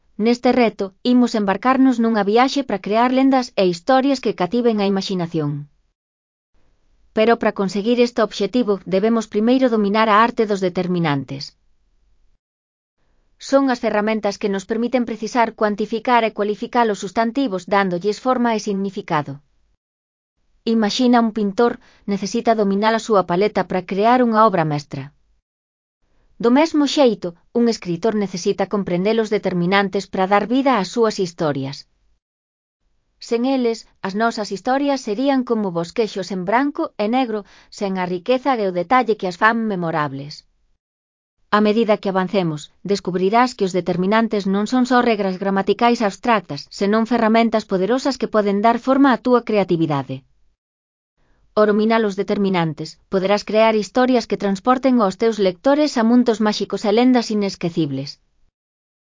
Elaboración propia (proxecto cREAgal) con apoio de IA voz sintética xerada co modelo Celtia. Introdución 3.1 (CC BY-NC-SA)